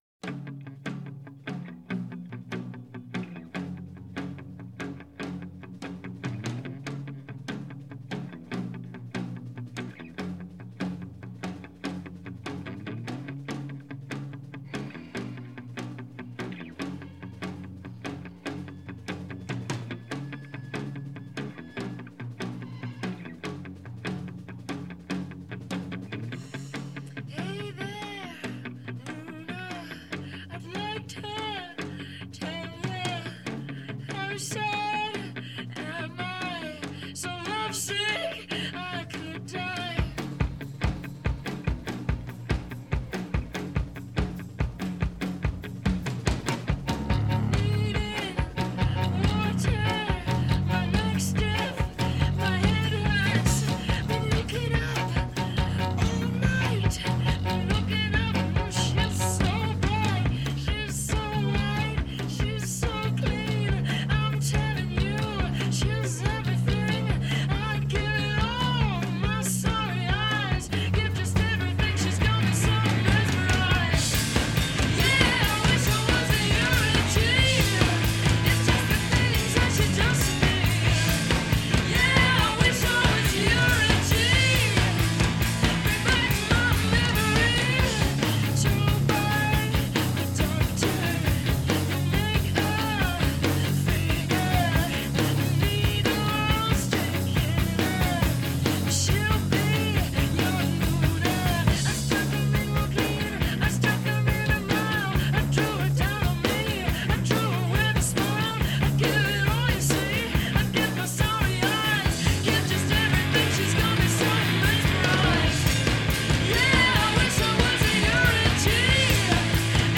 آهنگ ایندی راک آهنگ راک